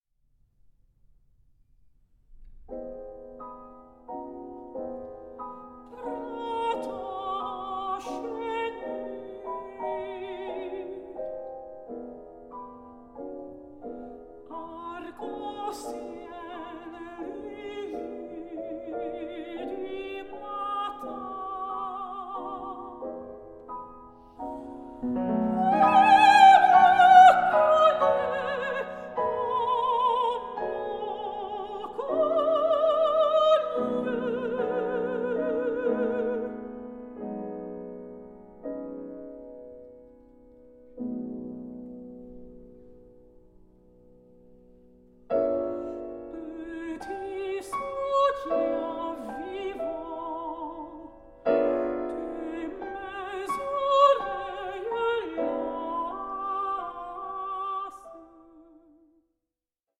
mezzo-soprano
pianist